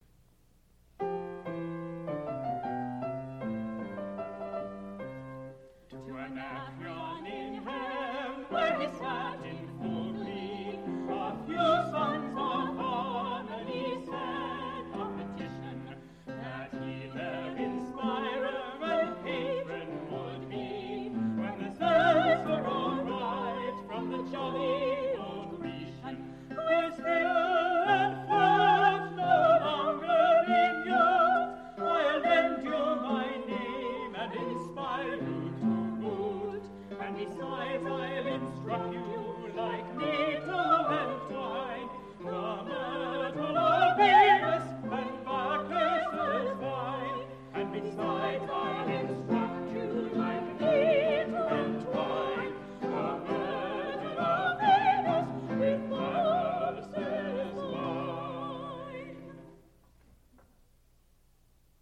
Spoken intro; Native American Music